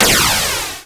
ihob/Assets/Extensions/RetroGamesSoundFX/Shoot/Shoot01.wav at master
Shoot01.wav